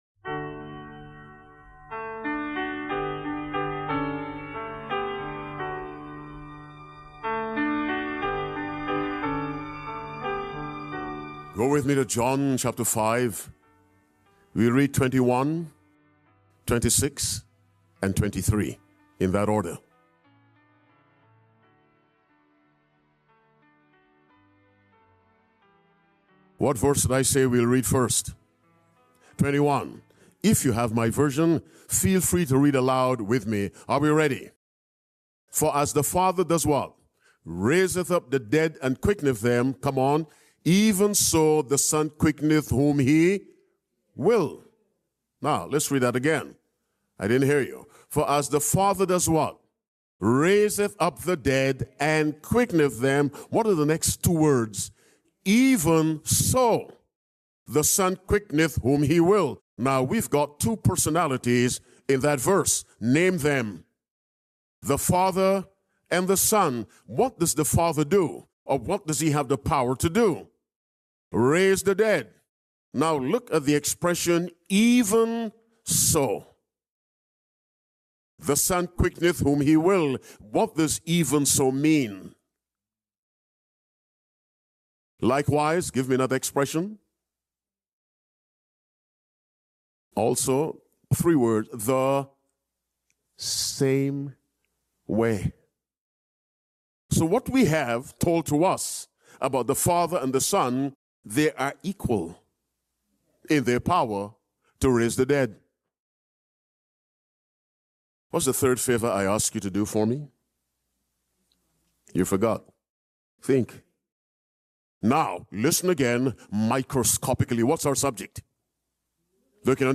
Discover the power of faith and divine truth in this compelling sermon, where the speaker explores the equality of the Father and the Son, the necessity of faith, and the call to center our lives on Christ. Through Scripture, we learn about Jesus as the Creator and Sustainer, the importance of daily surrender, and the transformative power of the spoken word.